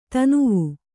♪ tanuvu